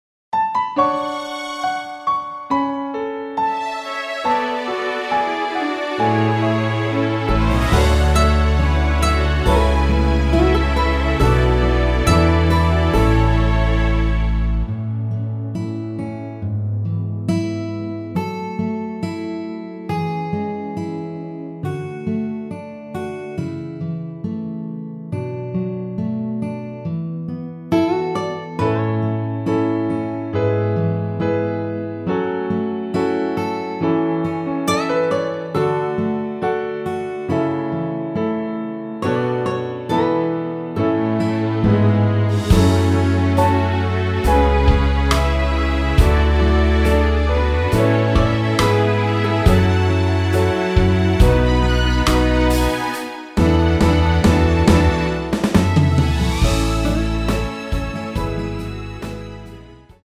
남성분이 부르실수 있도록 제작 하였습니다.(미리듣기 참조)
앞부분30초, 뒷부분30초씩 편집해서 올려 드리고 있습니다.